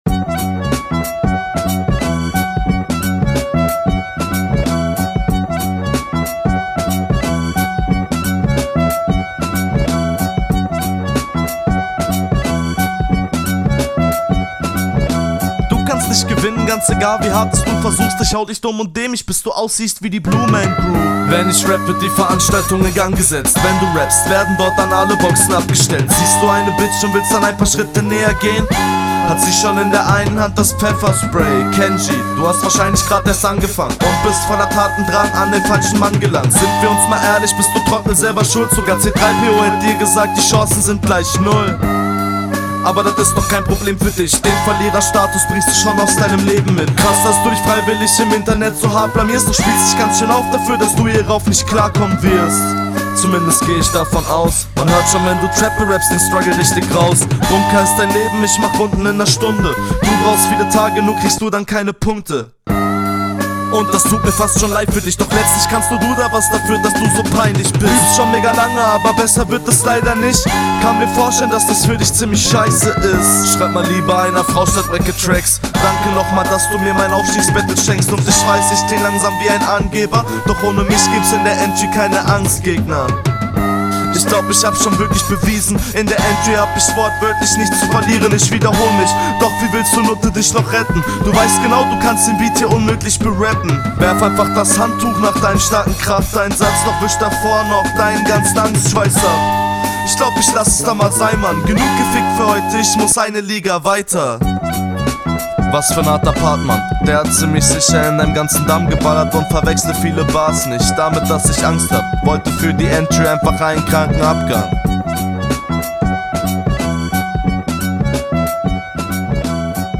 wieder cooler vibe, wobei ich finde, dass deine stimme nicht soo geil auf den beat …
Beat Mega, mag die Bassline, die dem Sample hinterrennt sehr.